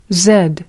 zed-uk.mp3